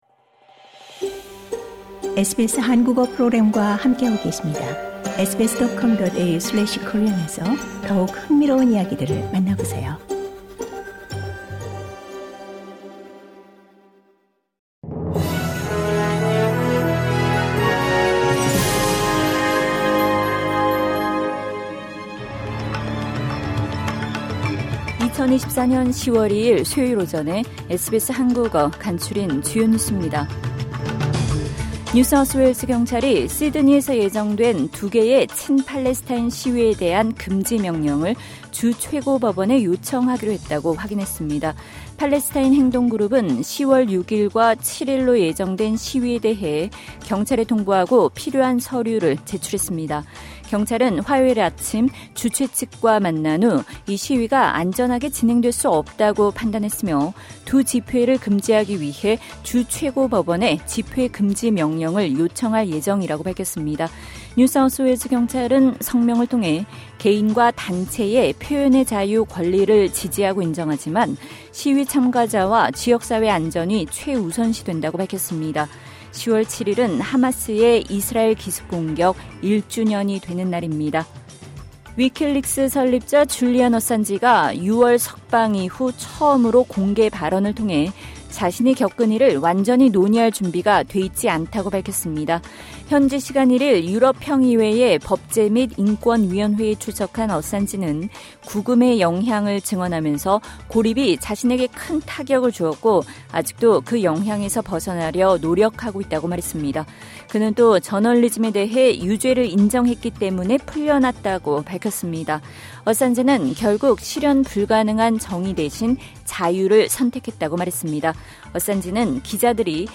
SBS 한국어 아침 뉴스: 2024년 10월 2일 수요일